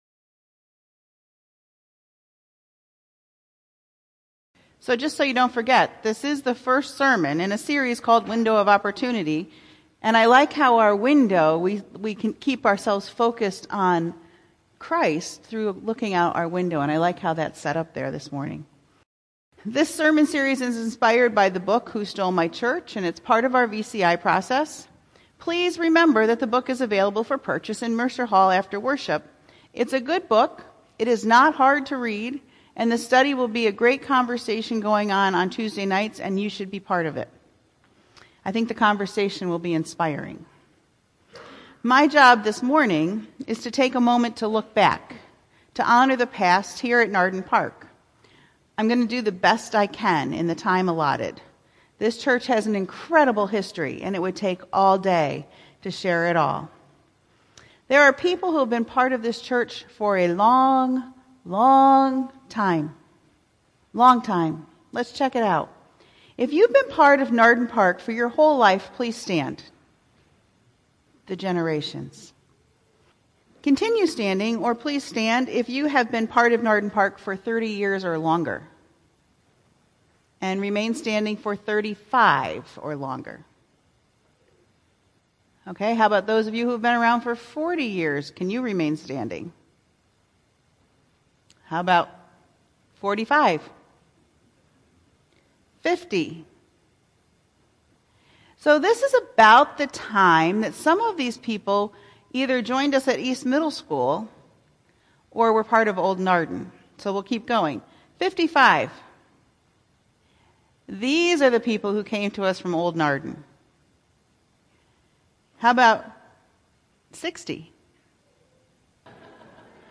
January 7, 2018 Sermon, "A Glance Back" • Nardin Park Church